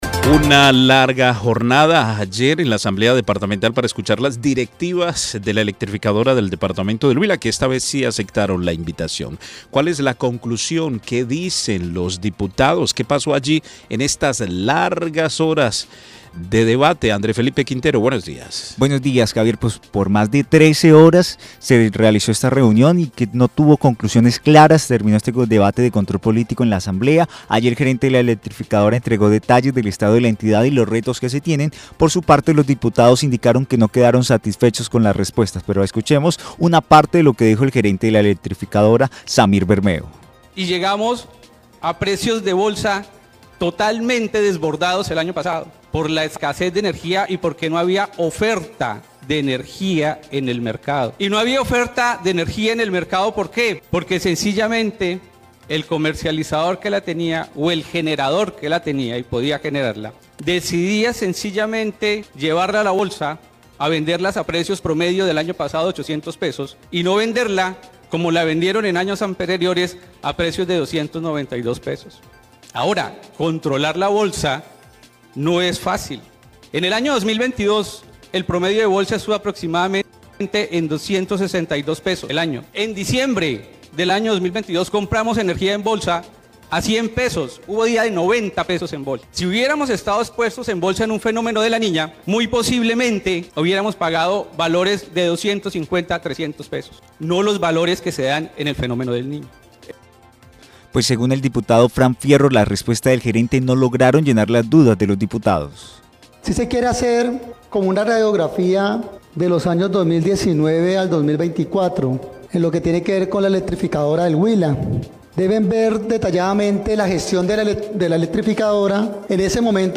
Sin conclusiones claras terminó el debate de control político en la Asamblea departamental.